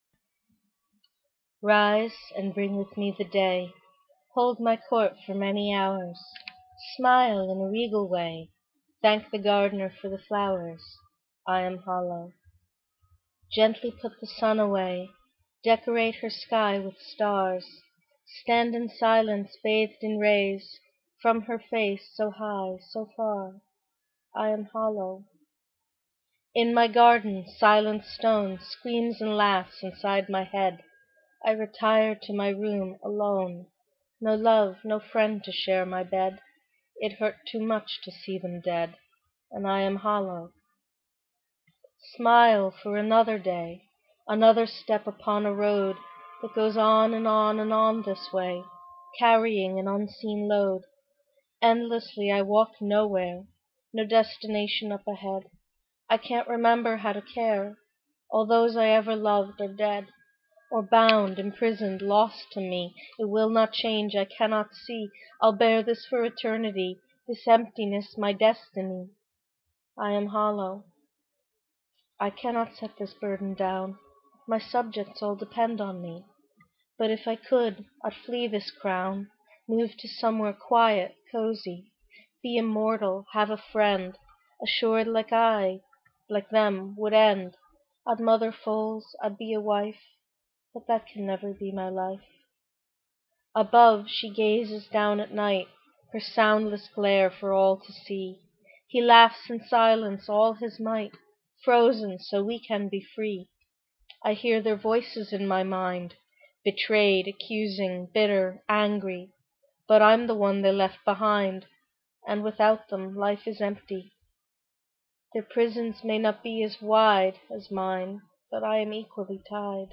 WMA Audio file of reading, by me, now uploaded at my own site.